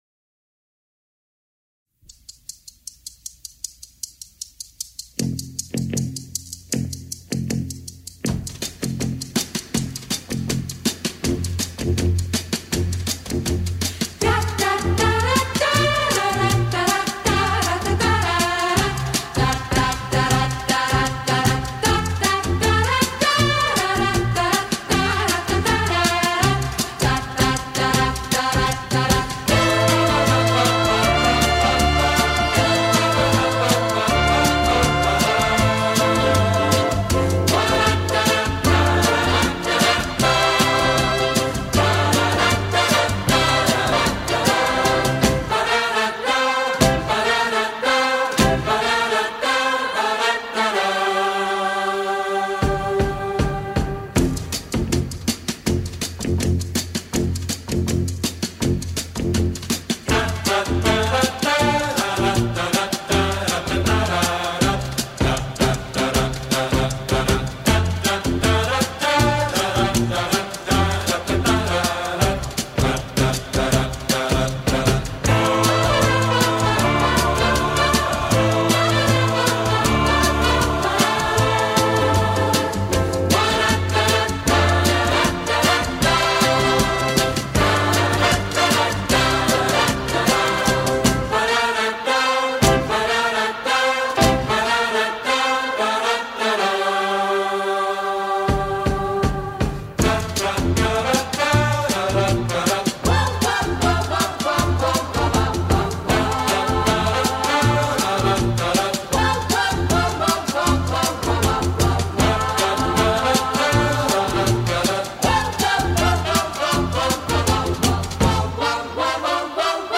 у меня только этот оркестр